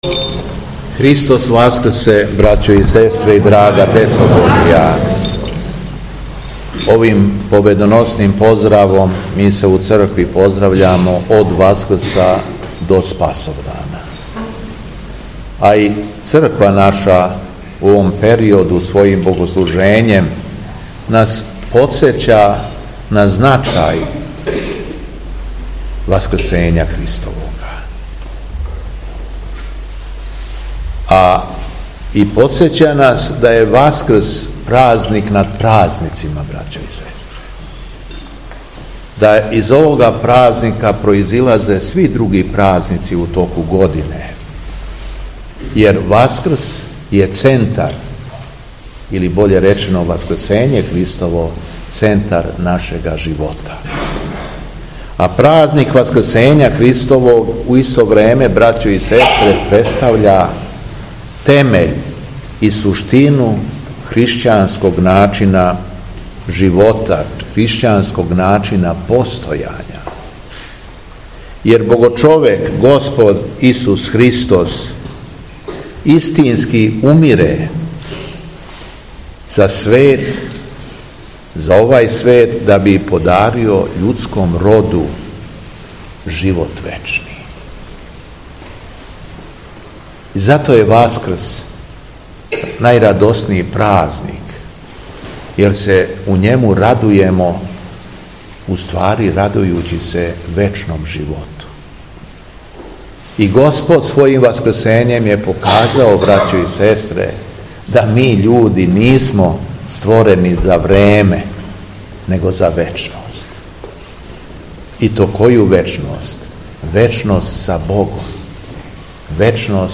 СВЕТА АРХИЈЕРЕЈСКА ЛИТУРГИЈА У ХРАМУ СВЕТОГ САВЕ И СВЕТОГ СИМЕОНА У КУСАТКУ
Беседа Његовог Преосвештенства Епископа шумадијског г. Јована